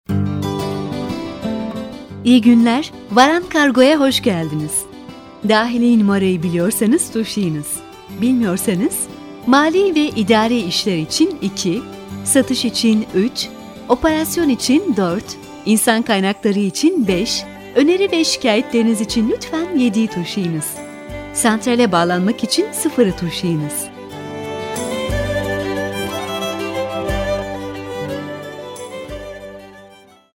Profesyonel studio ortamında gerçekleştirilen kayıtlar ile santral sistemleri için santral seslendirme anons kayıtları yapılmaktadır.
IVR Santral Seslendirme